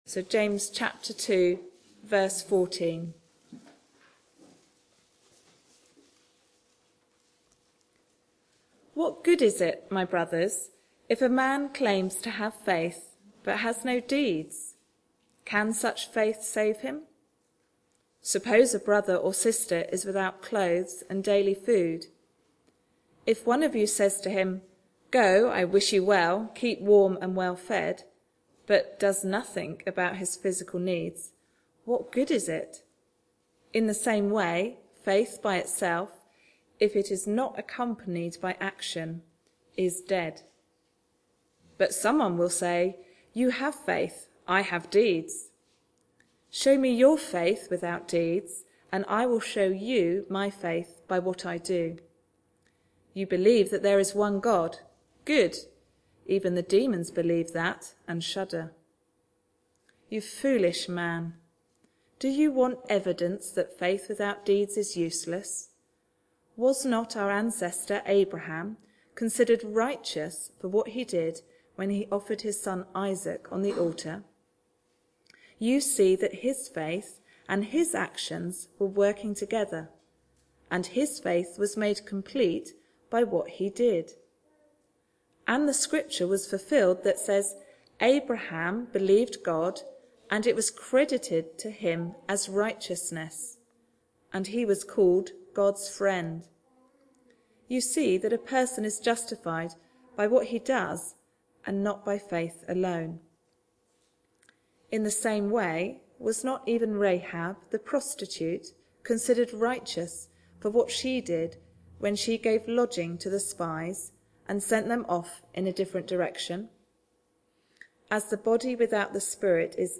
Back to Sermons Deeds of faith